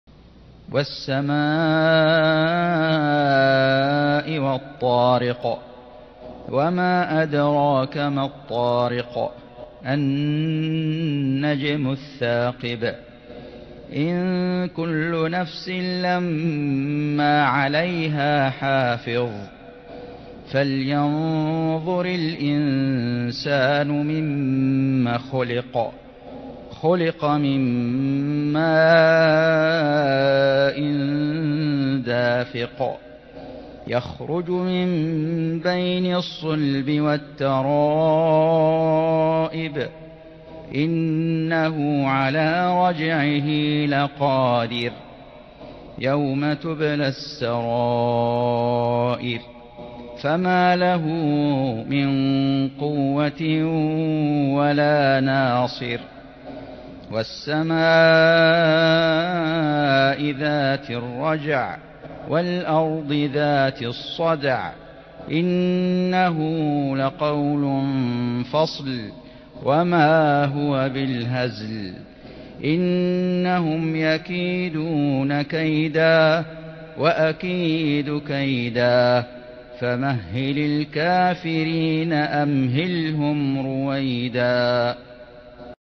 سورة الطارق > السور المكتملة للشيخ فيصل غزاوي من الحرم المكي 🕋 > السور المكتملة 🕋 > المزيد - تلاوات الحرمين